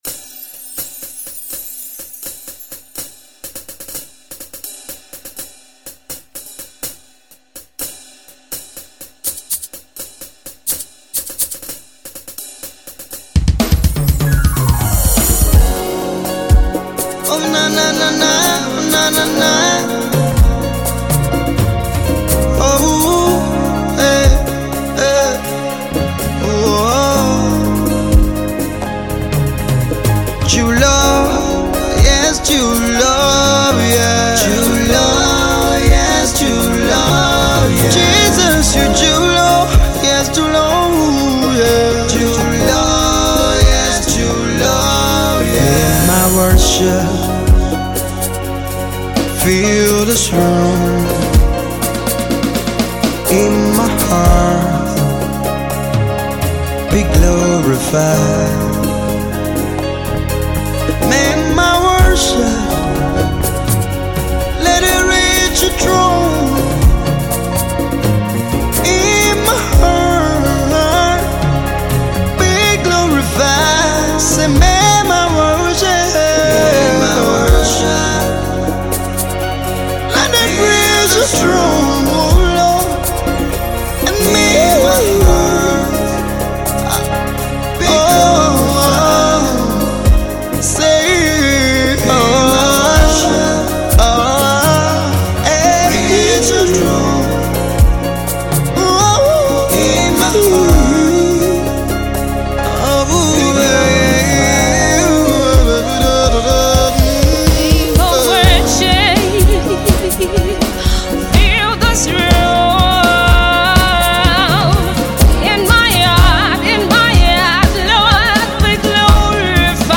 Gospel
sensational soul-lifting song